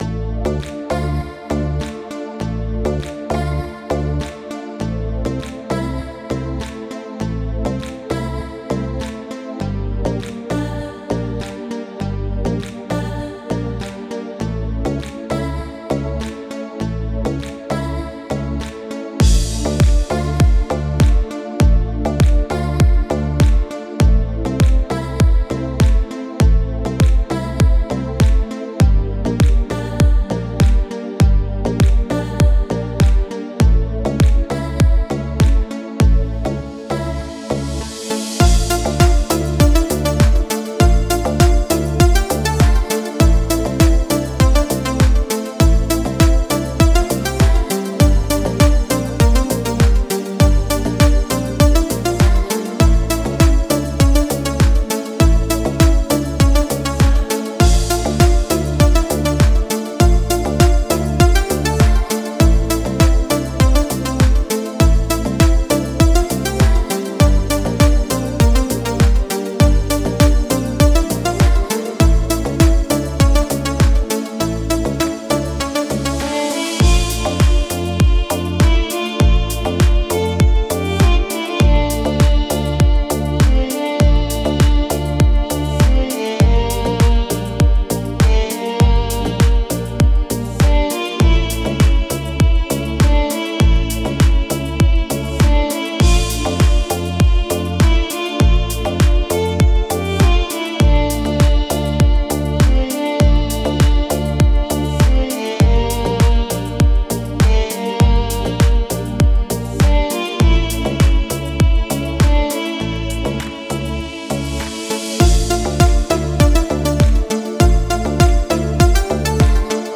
دیپ هاوس ریتمیک آرام موسیقی بی کلام